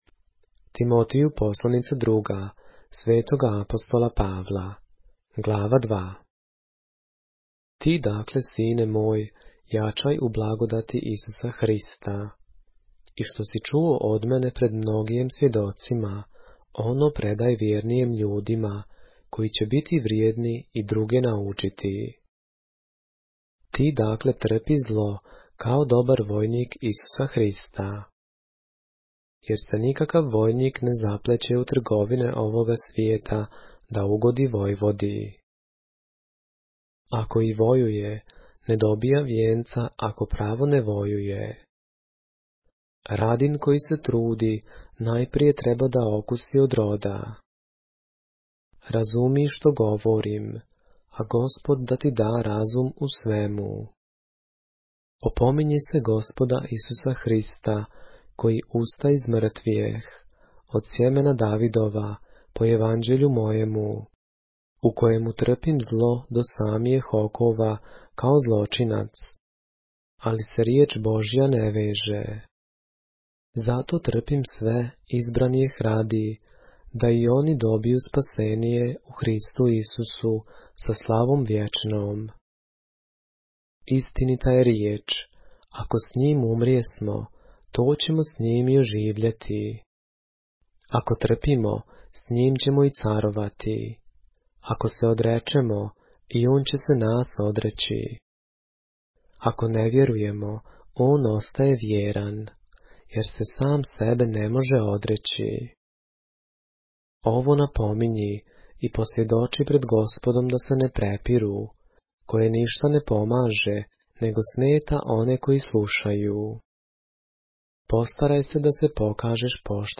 поглавље српске Библије - са аудио нарације - 2 Timothy, chapter 2 of the Holy Bible in the Serbian language